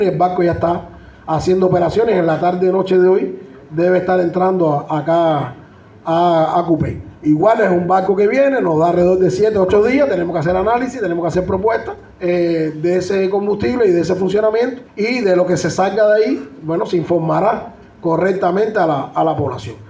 Al respecto Adiel Morera Macías, durante la XXI sesión de la Asamblea Municipal del Poder Popular, que tuvo lugar este domingo en la sede del Órgano de Gobierno, explicó las medidas adoptadas en la localidad a partir del déficit de combustible y baja disponibilidad para la generación, lo cual trae consigo las afectaciones del servicio programado por circuitos.